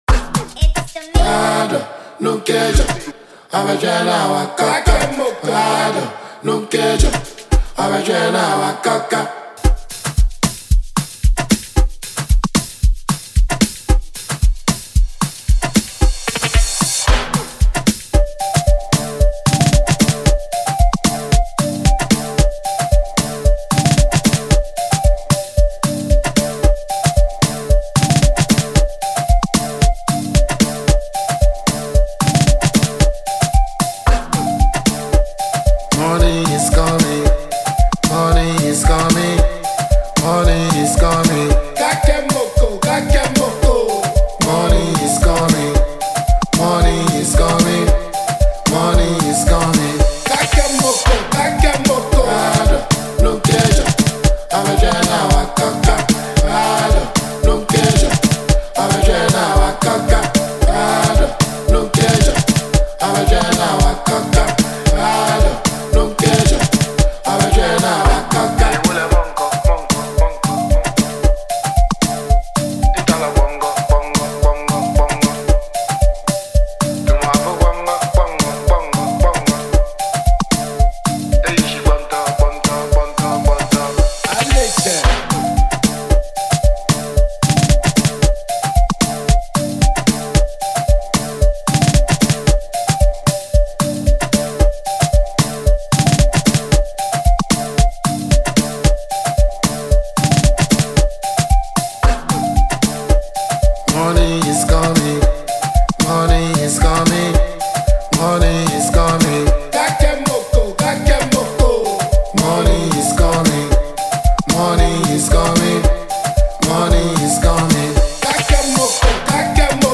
New from the Dancehall King